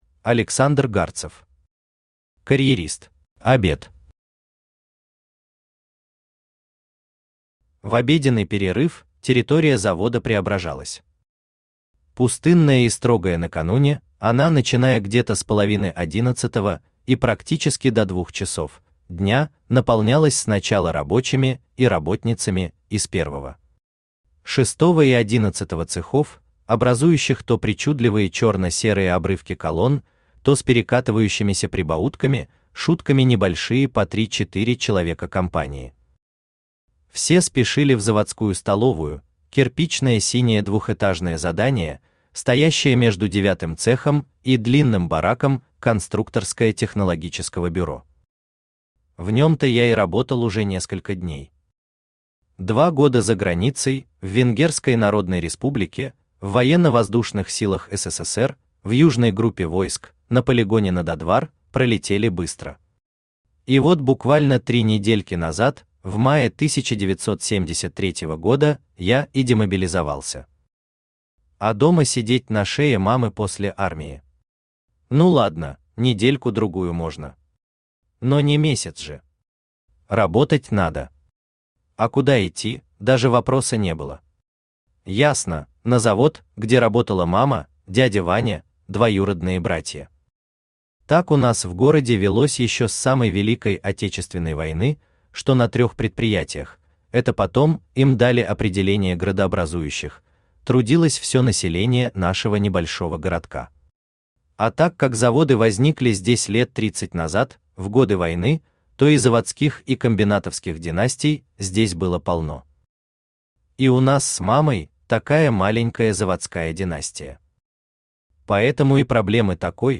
Aудиокнига Карьерист Автор Александр Гарцев Читает аудиокнигу Авточтец ЛитРес. Прослушать и бесплатно скачать фрагмент аудиокниги